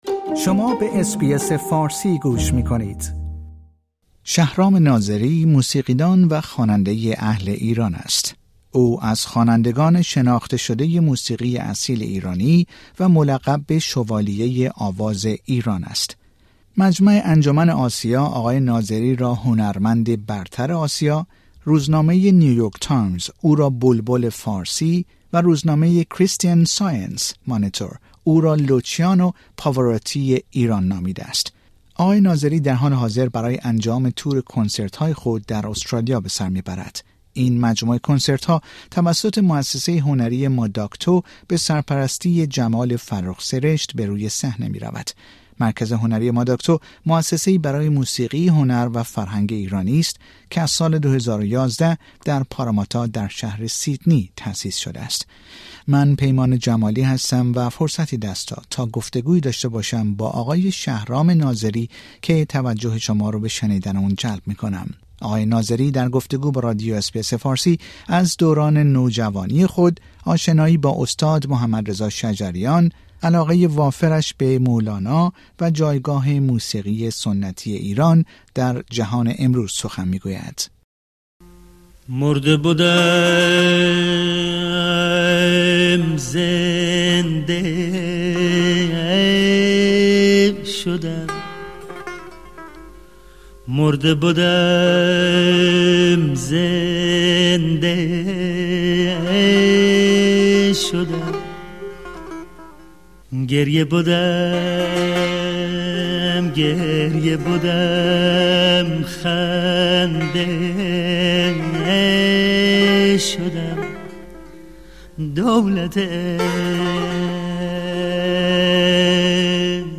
گفتگوی اختصاصی با «شوالیه آواز ایران»